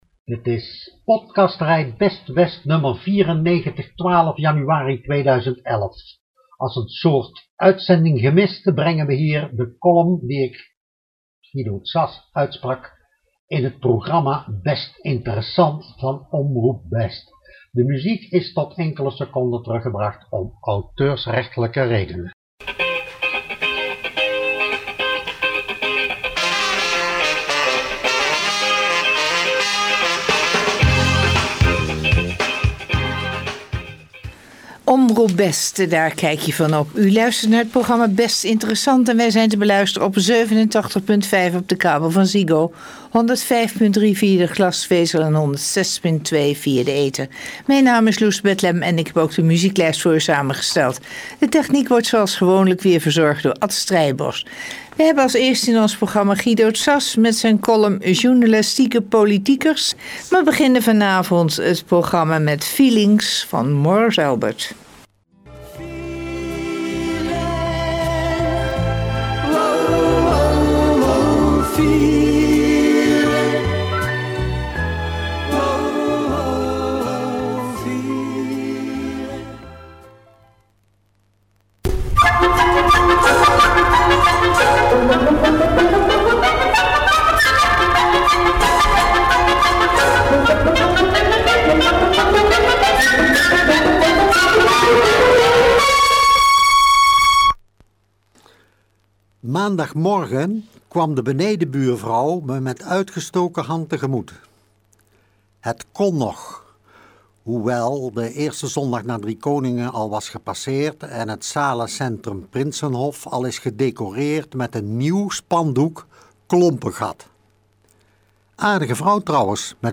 (Radiocolumn, Omroep Best, 12.01.11)